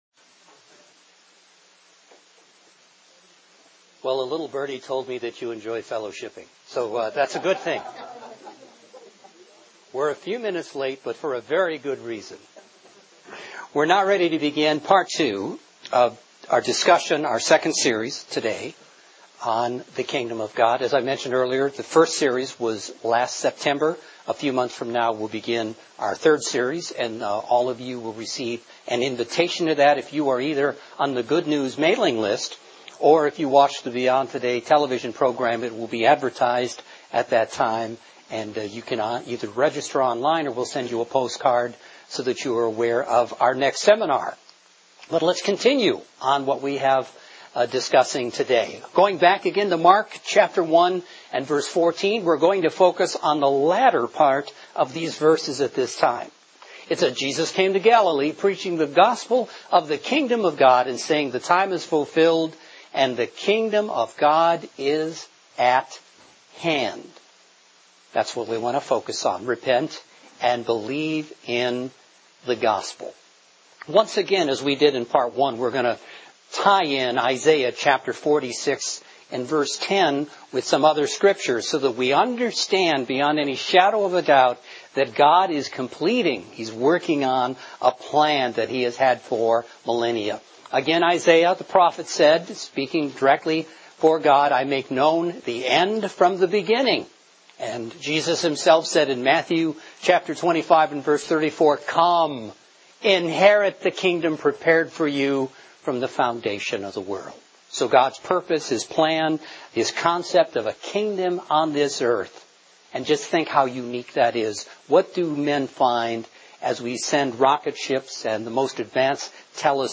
Mankind's problems are a problem of the heart that can only be solved by the return of Jesus Christ. Jesus preached the good news of the coming Kingdom of God. Learn about God's purpose and plan in this Kingdom of God seminar.